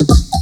DRUMFILL15-L.wav